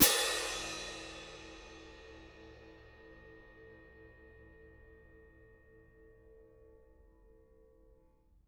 cymbal-crash1_mf_rr1.wav